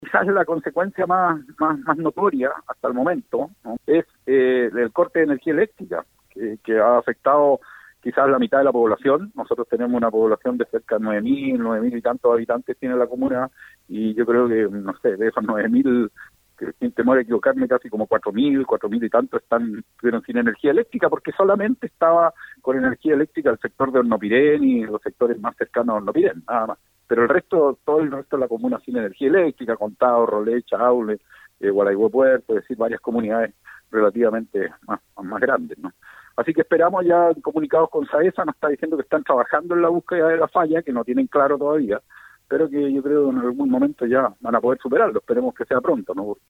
En otros puntos de la región, puntualmente en Hualaihué, uno de los efectos fue el corte de luz para gran parte de la población durante esta mañana, indicó el alcalde Fredy Ibacache.